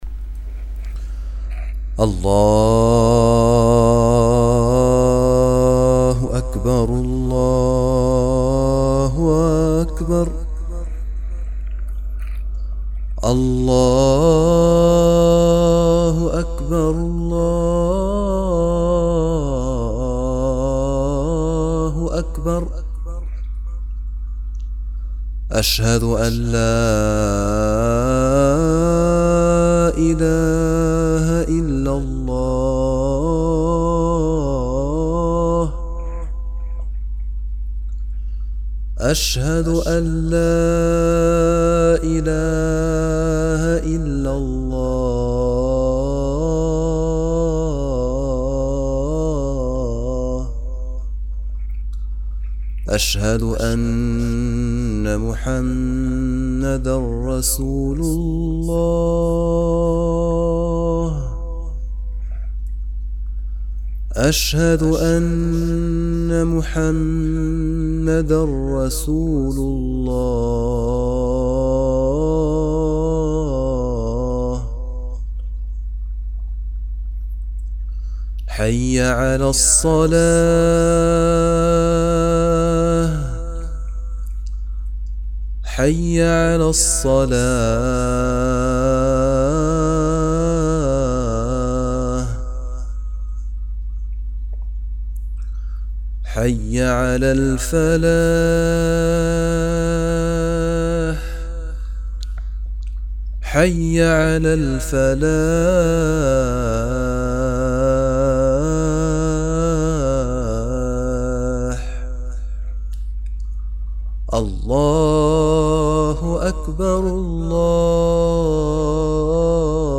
أناشيد ونغمات
أذان